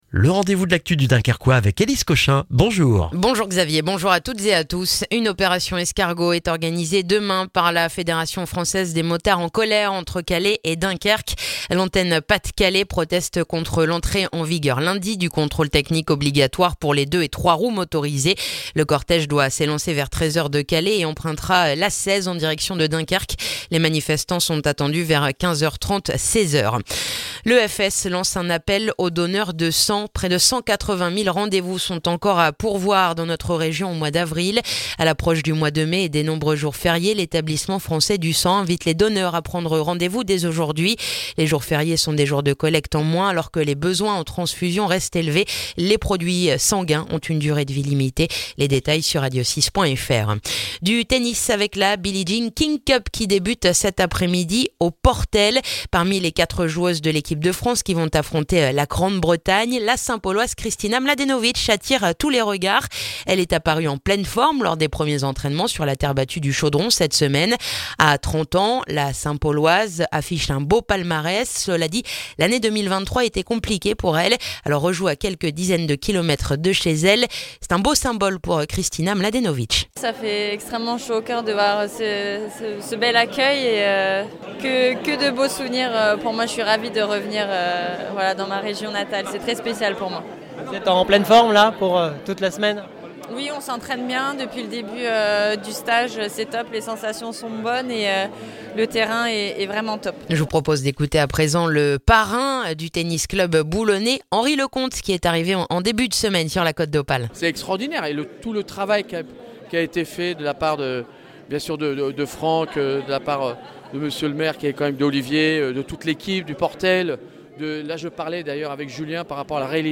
Le journal du vendredi 12 avril dans le dunkerquois